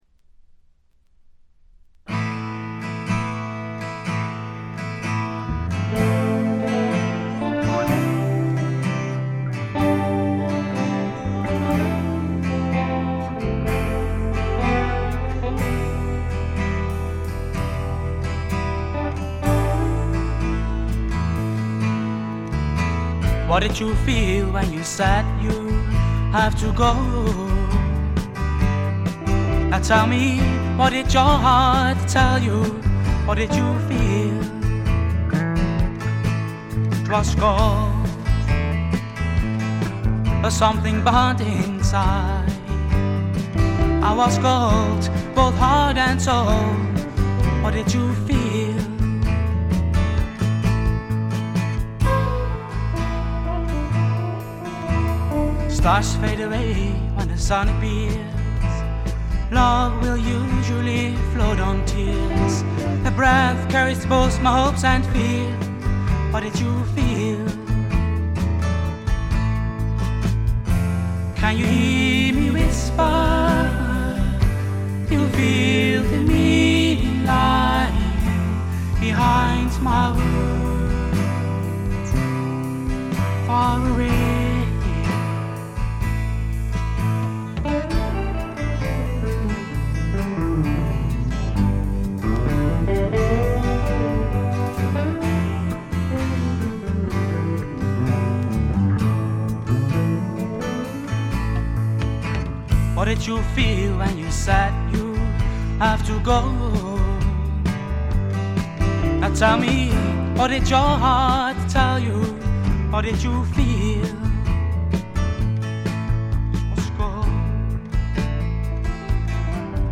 軽いチリプチが少々。
全体を貫く哀愁味、きらきらとしたアコースティックな美しさは文句なしに至上のもの。
試聴曲は現品からの取り込み音源です。